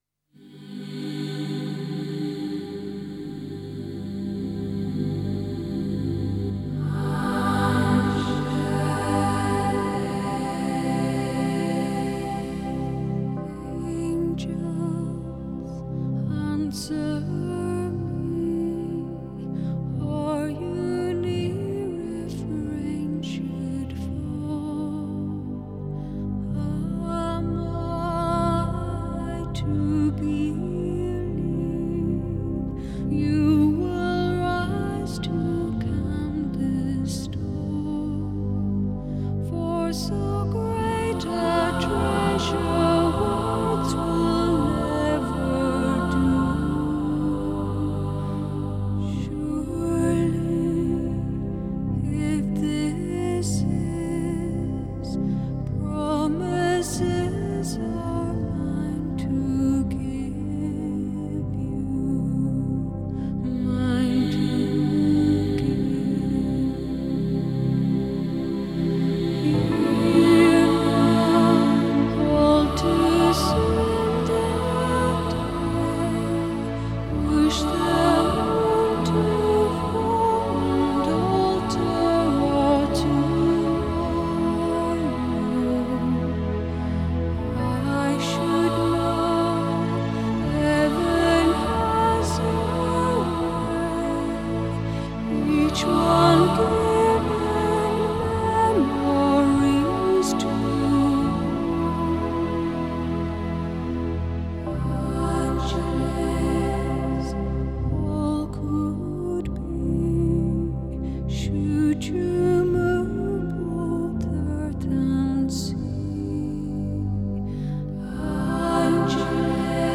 장르: Electronic, Folk, World, & Country
스타일: New Age, Modern Classical, Ambient